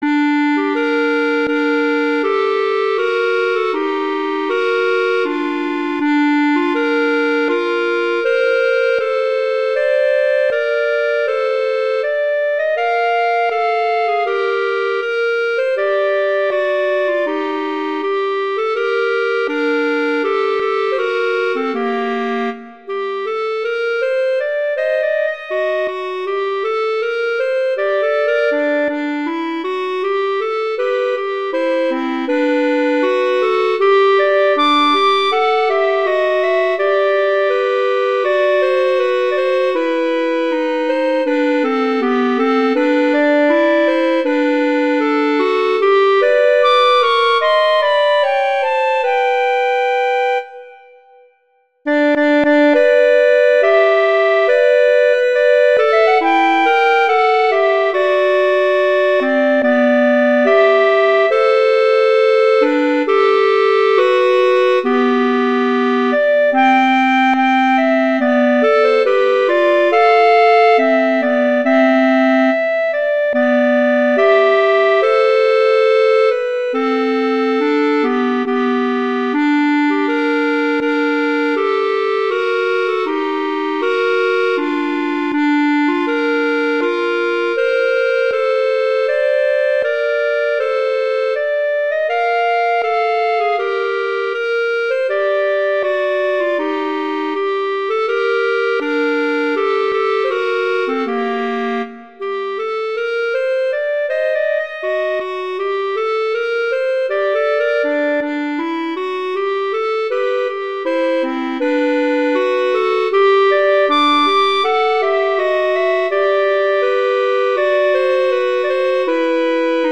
Instrumentation: two clarinets
arrangements for two clarinets